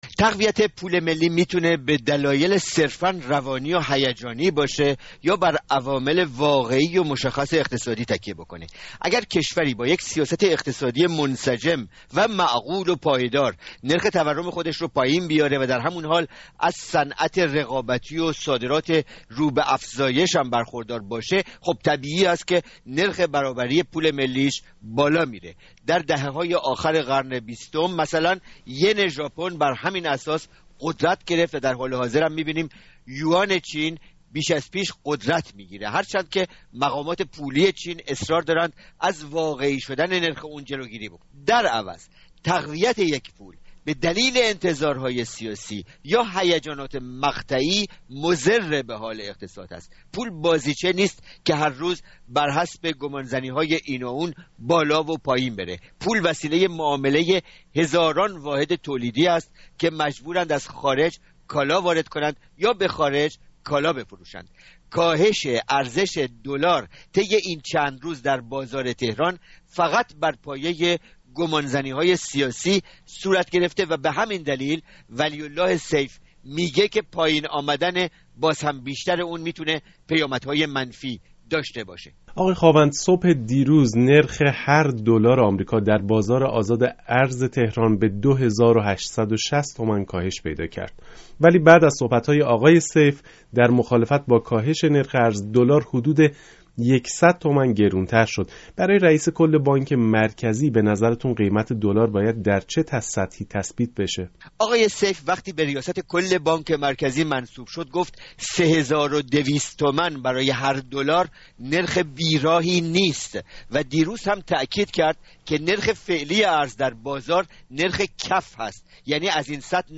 گفتگوی رادیو فردا